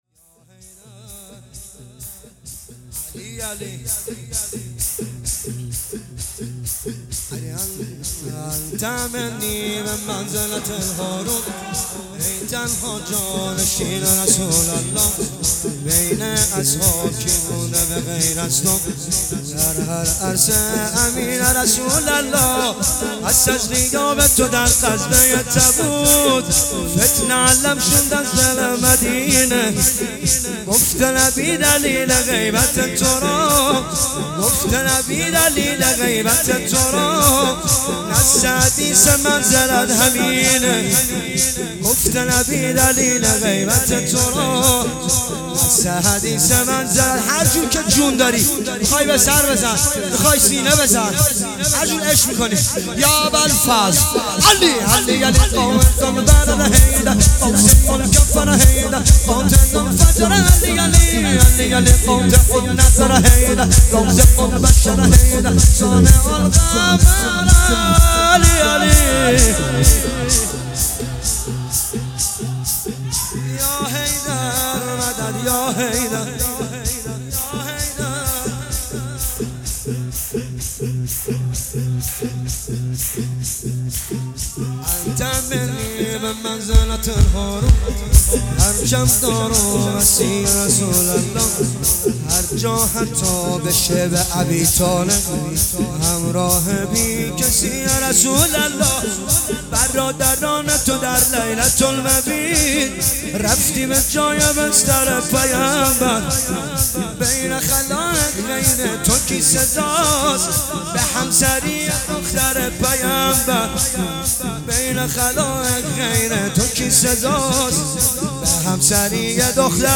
تک نوحه